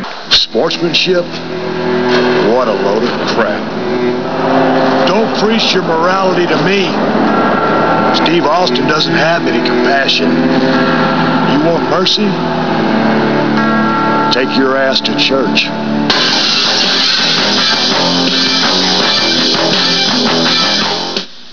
Great promo: you want mercy, then take your ass to church! (237.3k)